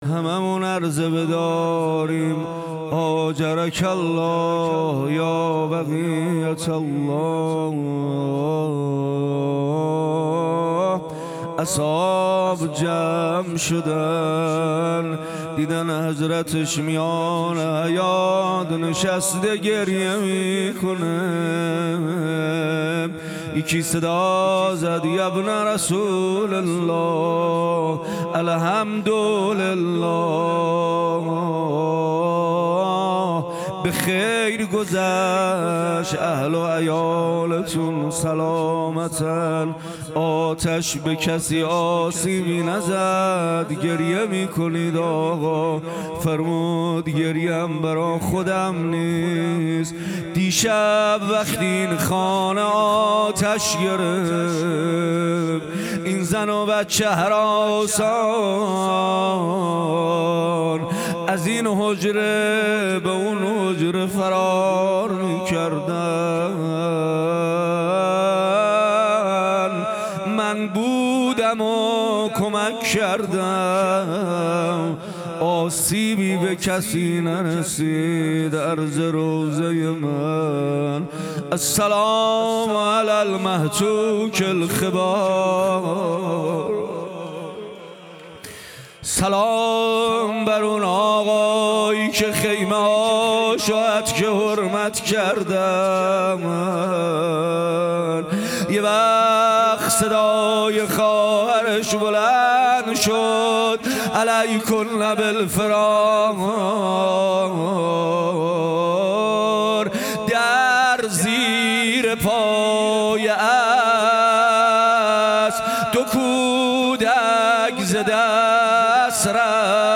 روضه امام صادق علیه السلام